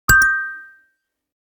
notification_026.ogg